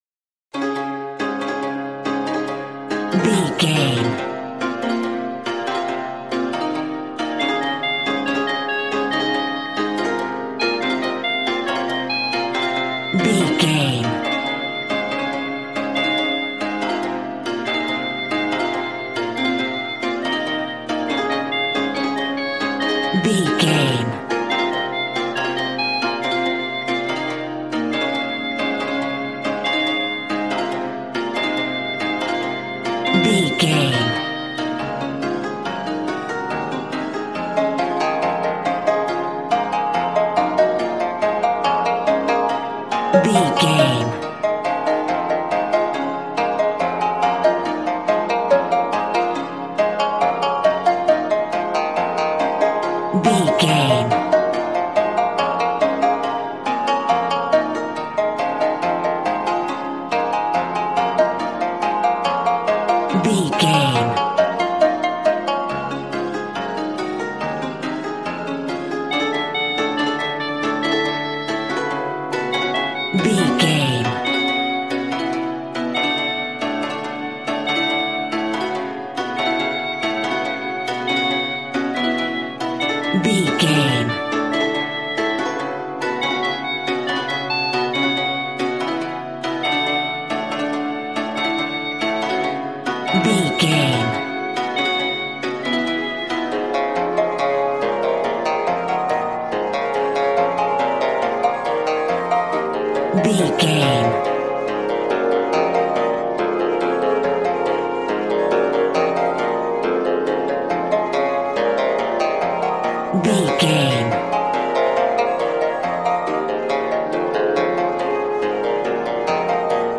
Aeolian/Minor
B♭
smooth
conga
drums